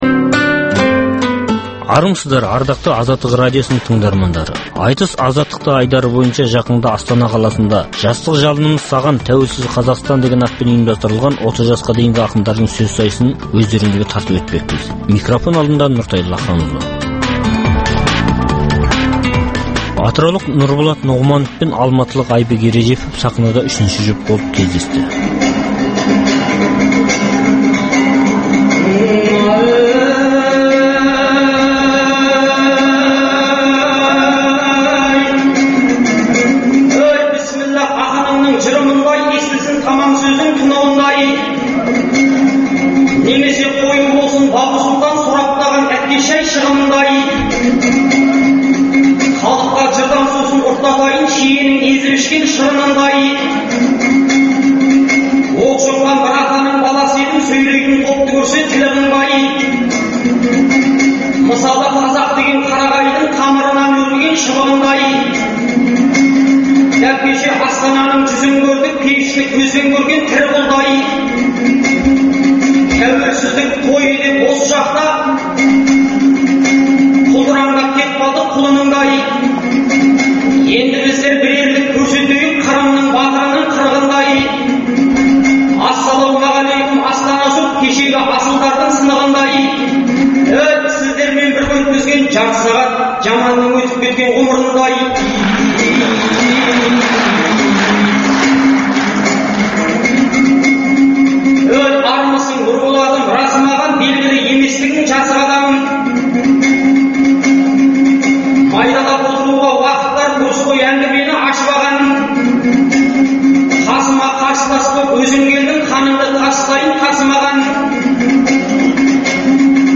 Жақында Астана қаласында өткен жастар айтысынан келесі жұптың сөз сайысын тыңдаңыздар.